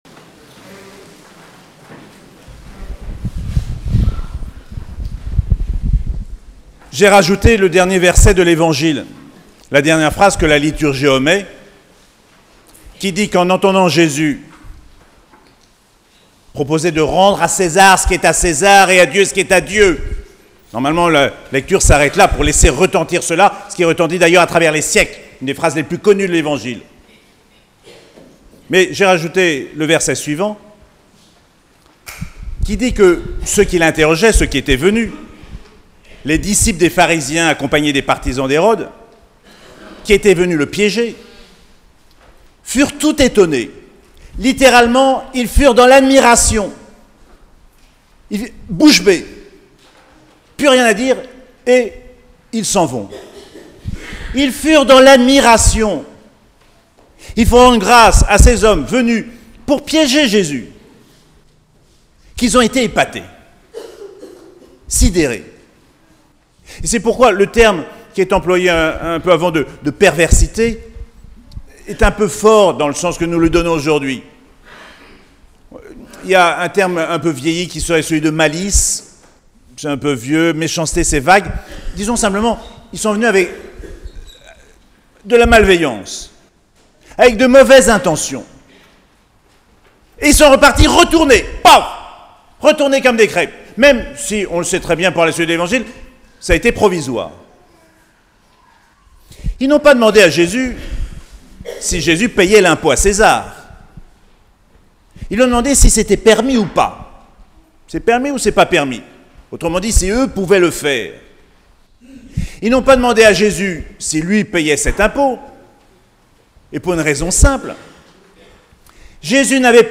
29ème dimanche du temps ordinaire - 22 octobre 2023
Toutes les homélies